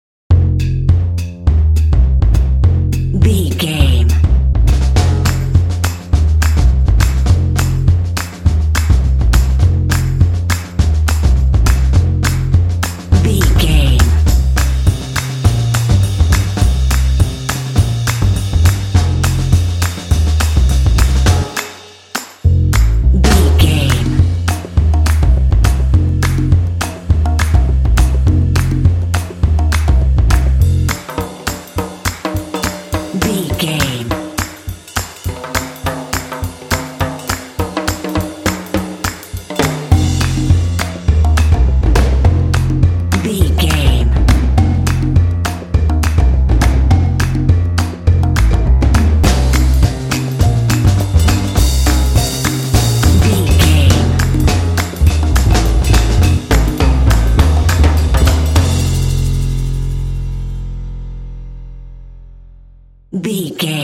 Aeolian/Minor
groovy
cool
sultry
drums
bass guitar